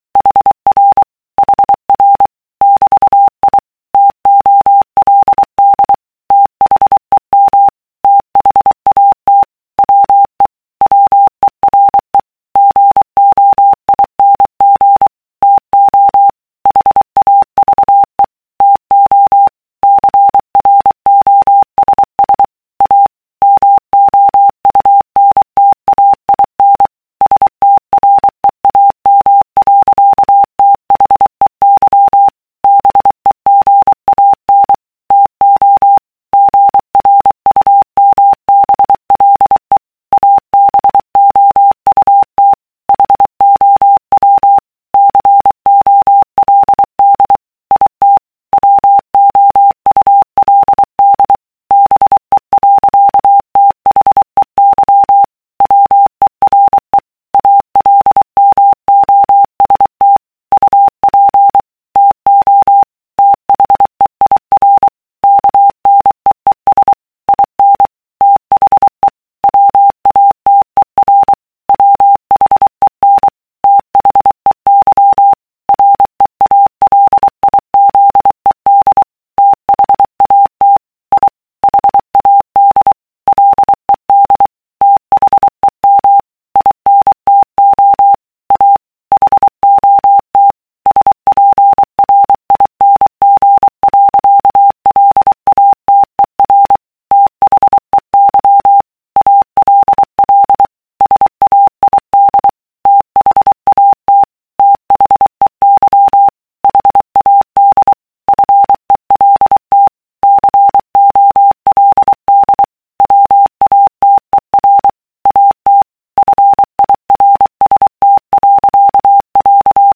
欧文・・・　スピード聞き比べ　あくまでもこのくらい？
欧文　100字／分（PARIS_106）